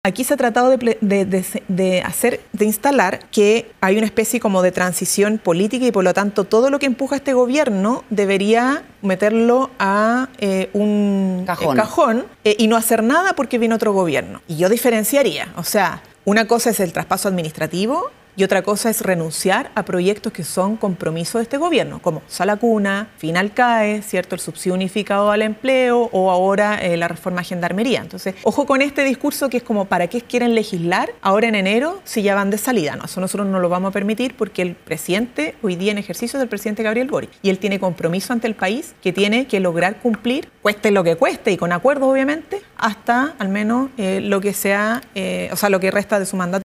“Hay como una especie de congelamiento en las conversaciones con los parlamentarios de oposición, después de haber nosotros flexibilizado harto las posiciones, y pareciera ser que el Presidente electo (José Antonio Kast) llamó a tirarle la cadena. Yo espero que así no sea”, dijo la vocera en el programa “Estadio Nacional” de TVN.